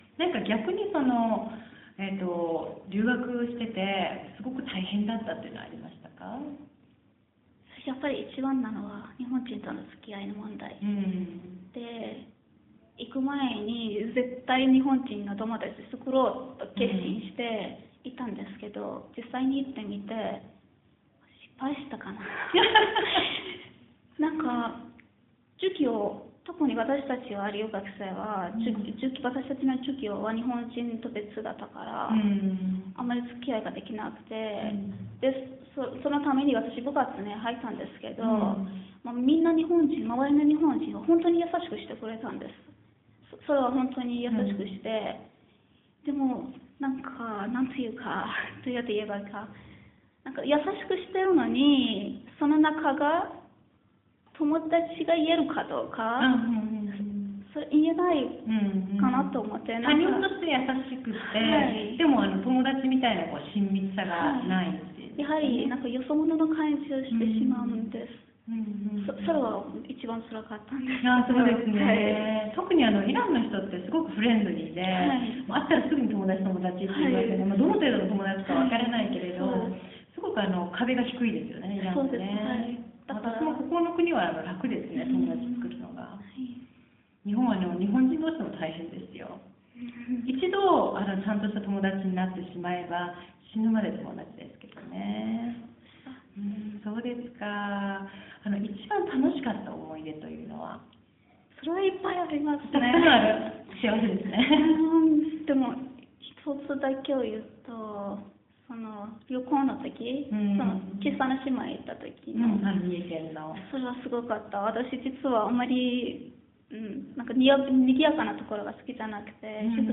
テヘラン大学日本語学科3年生へのインタビュー（３）
テヘラン大学日本語学科3年生へのインタビュー、最終回をお送りいたします。